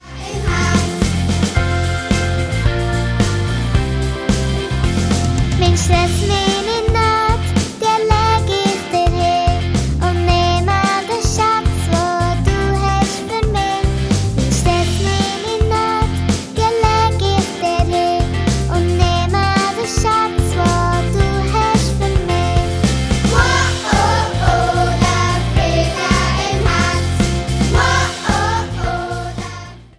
Weihnachtsmusical